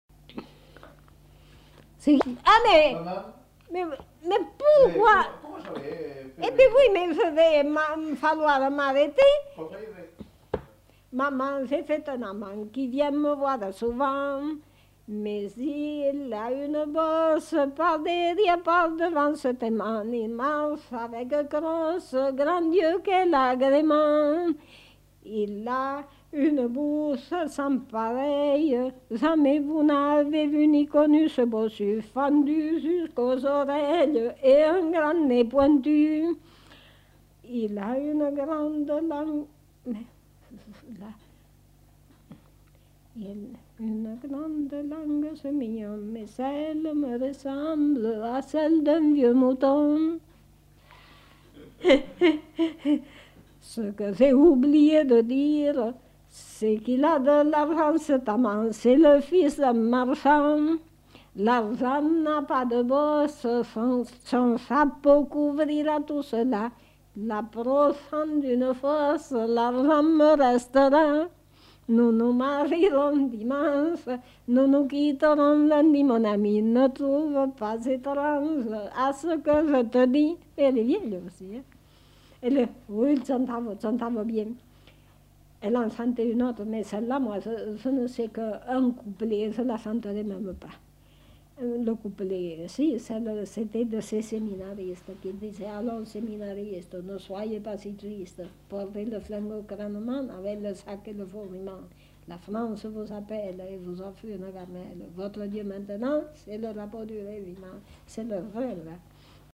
Aire culturelle : Périgord
Lieu : La Chapelle-Aubareil
Genre : chant
Effectif : 1
Type de voix : voix de femme
Production du son : chanté
Classification : satiriques, plaisantes diverses
Notes consultables : En fin de séquence, un fragment de chant.